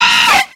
Cri de Chenipotte dans Pokémon X et Y.